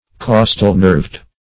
Search Result for " costal-nerved" : The Collaborative International Dictionary of English v.0.48: Costal-nerved \Cos"tal-nerved`\ (k?s"tal-n?rvd`), a. (Bot.) Having the nerves spring from the midrib.